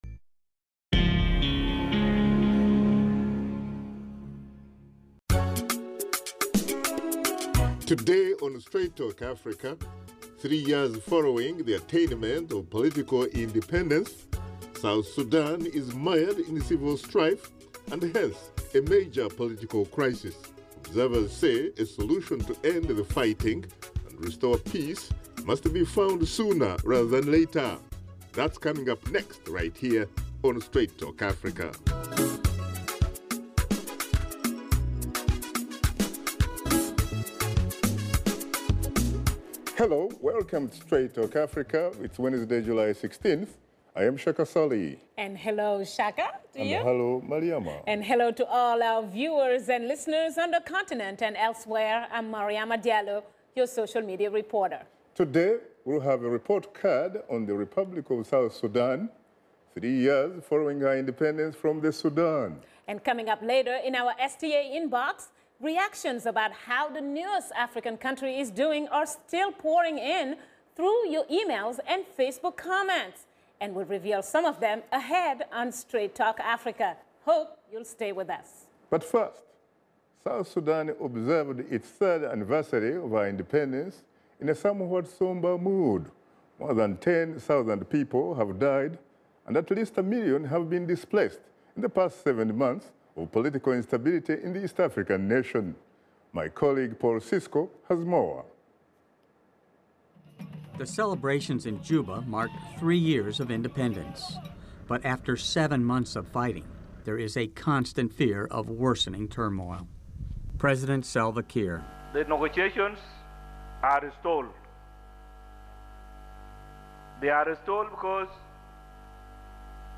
via UN Remote Studio